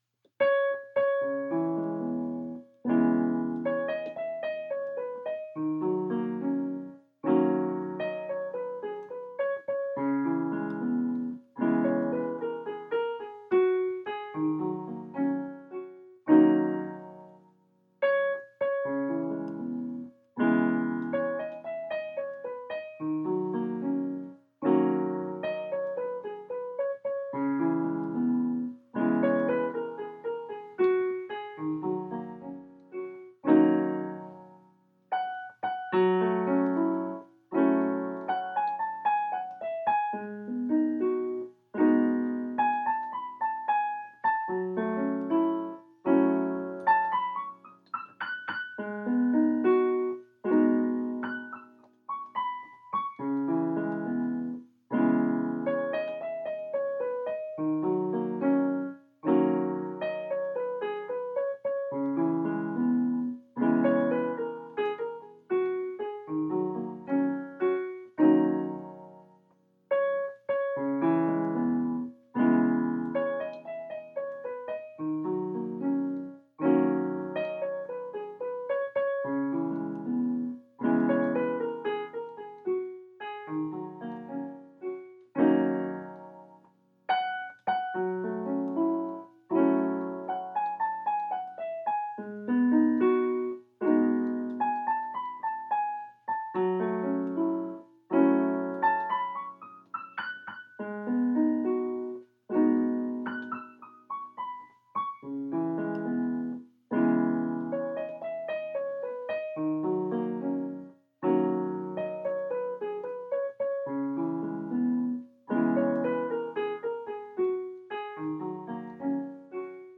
Here’s a brand new short song I recorded while practicing on the piano. It’s titled Equanimity and is in the key of D♭.